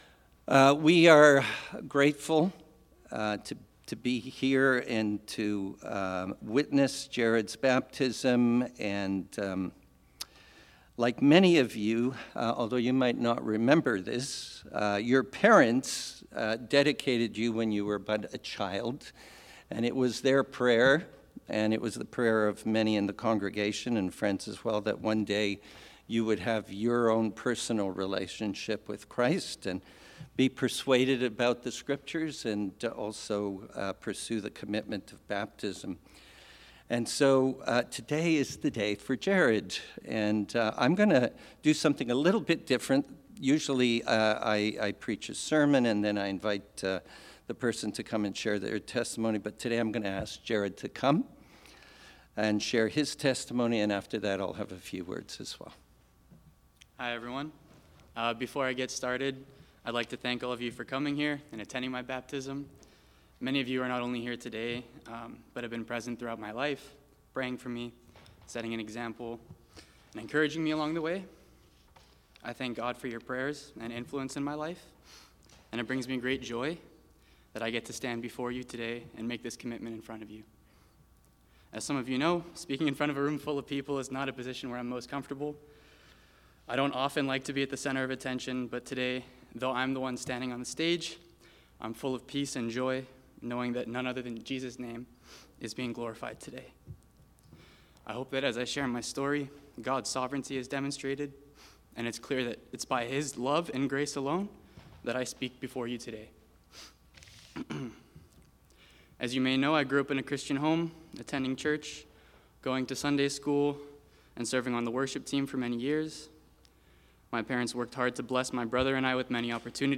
Baptism Testimony